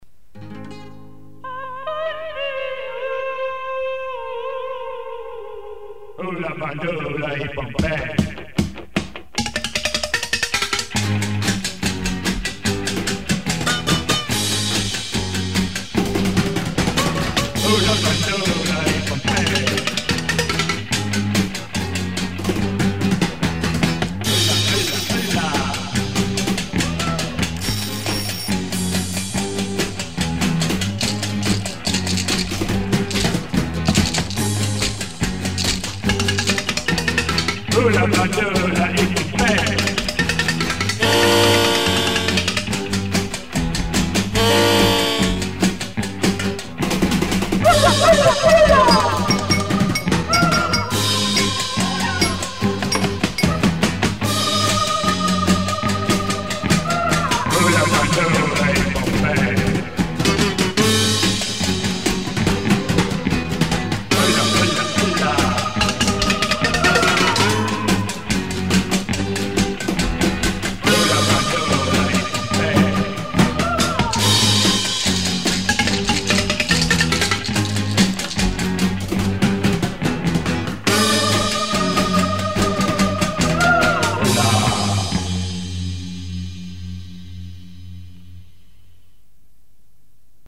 Percussion instruments
Guitar, Voice, Various instruments